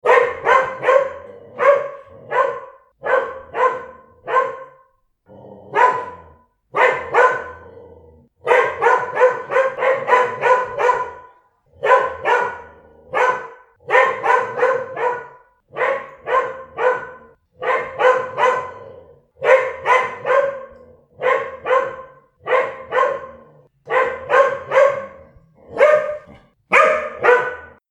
Big Dog Barking Téléchargement d'Effet Sonore
Big Dog Barking Bouton sonore